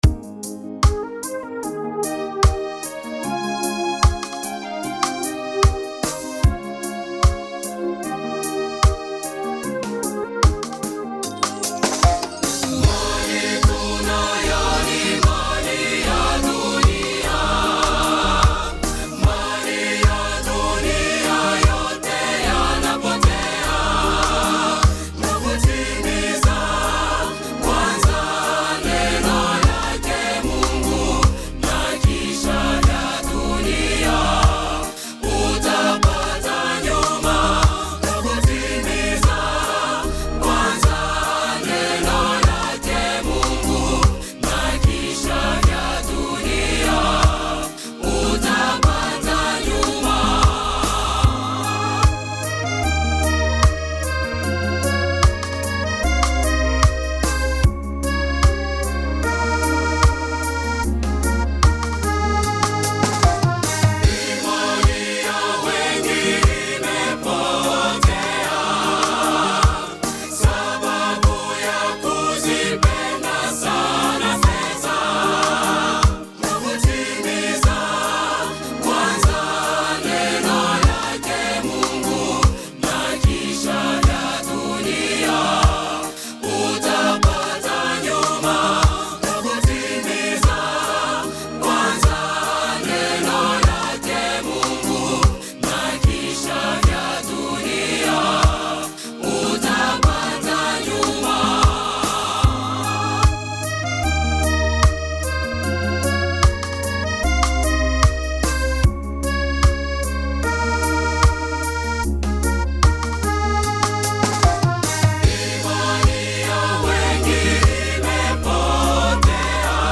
Chants de Méditation Téléchargé par